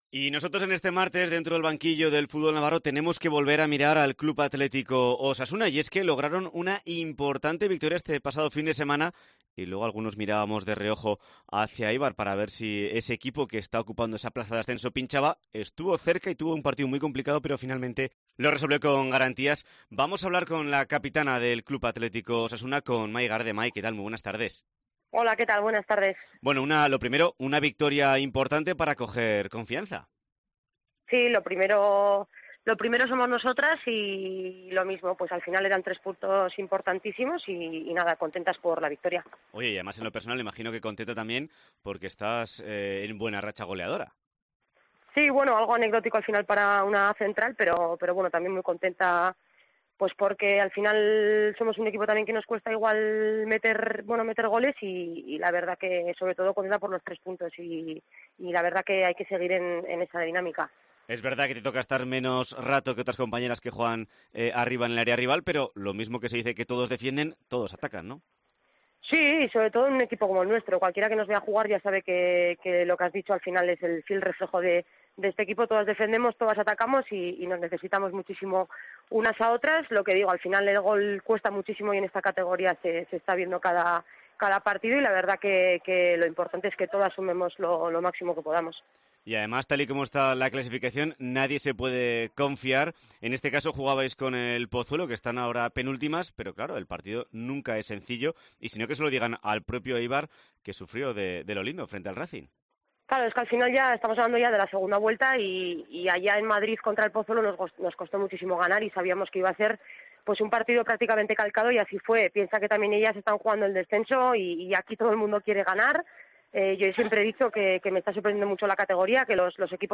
Repasamos con sus protagonistas la historia de Osasuna femenino